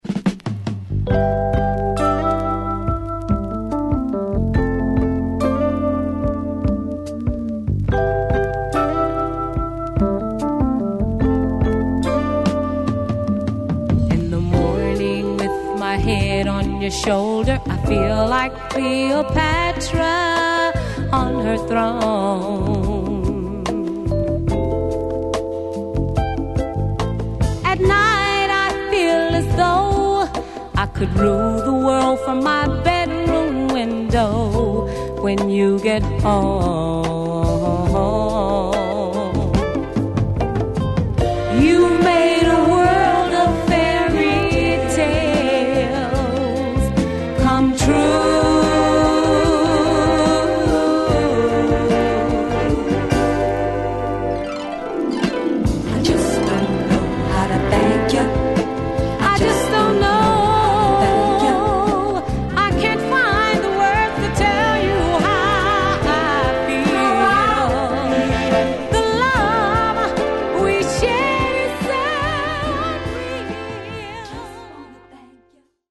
・ 45's SOUL / FUNK / DISCO / JAZZ / ROCK